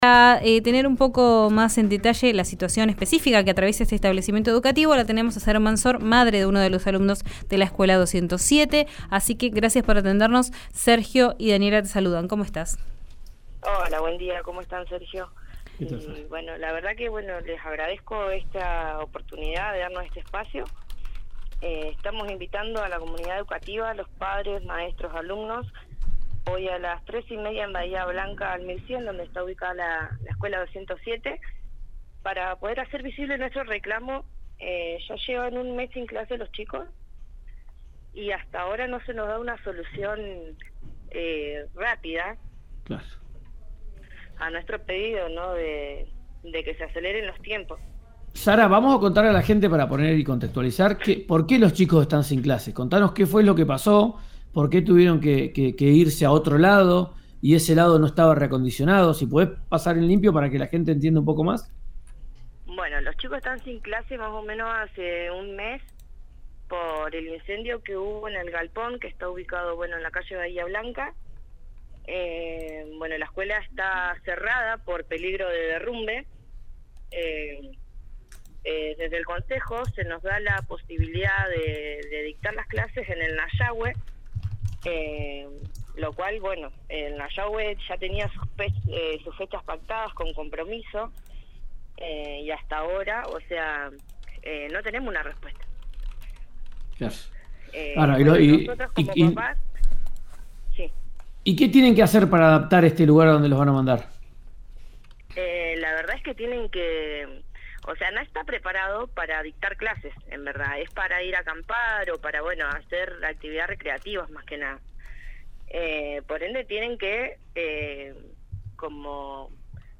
La mamá de una estudiante contó a RÍO NEGRO RADIO que desde entonces, los alumnos realizan sus tareas en casa, hasta que se complete el traslado al Centro Educativo Nayahue, lo cual está tomando más tiempo del esperado, según las familias.